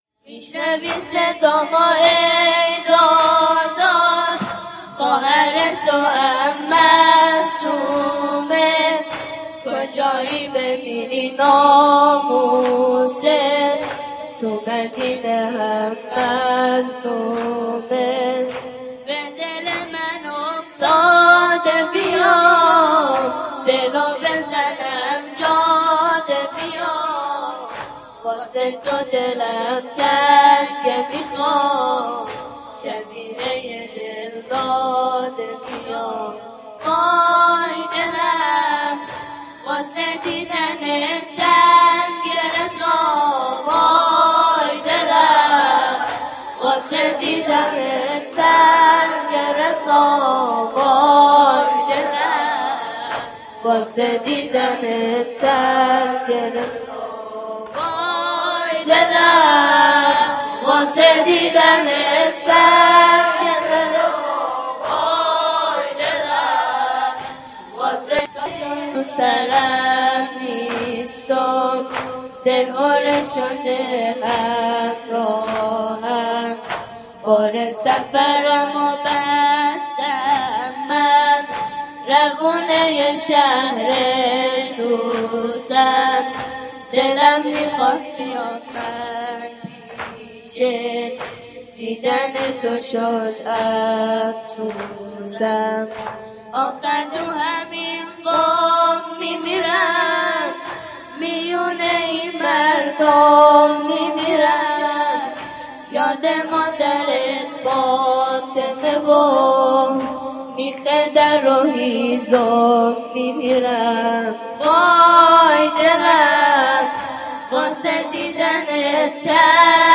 زمینه - میشنوی صدامو ای داداش خواهر توام - شب وفات حضرت معصومه ۱۳۹۷ - محفل فدائیان زینب کبری بشرویه